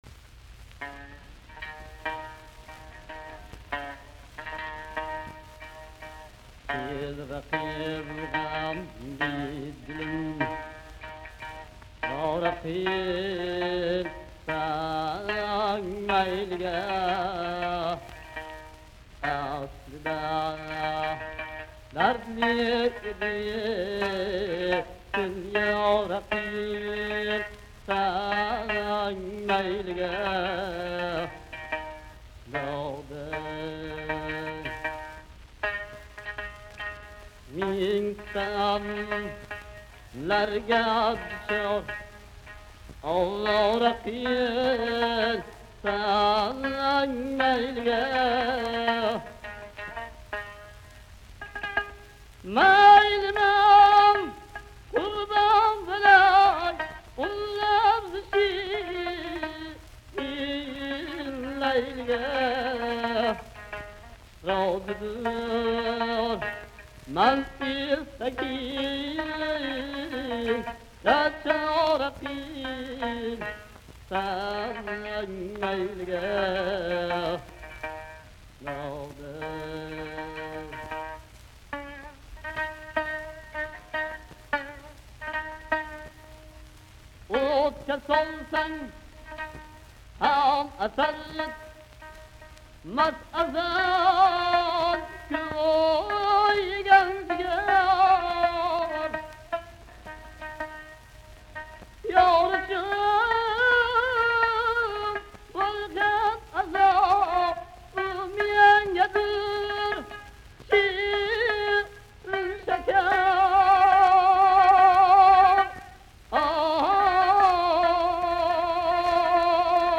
From Uzbekistan. Folk song, with tar.